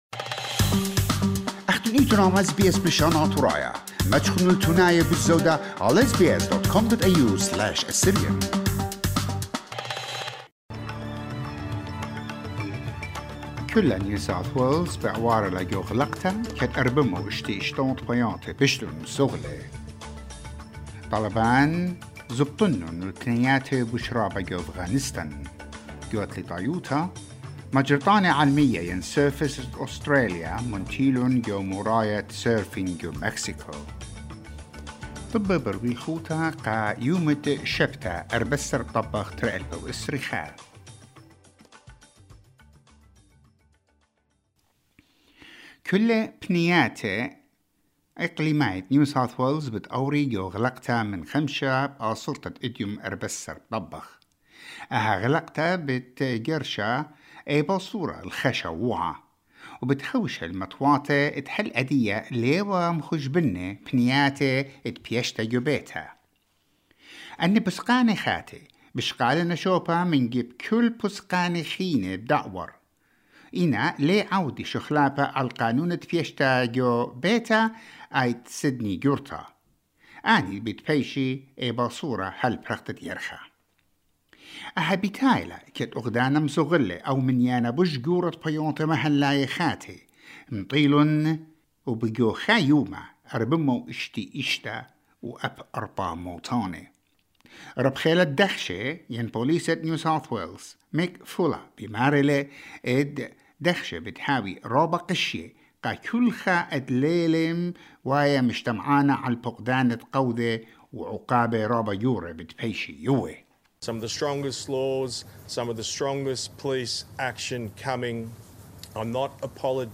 SBS NEWS IN ASSYRIAN 14 AUGUST 2021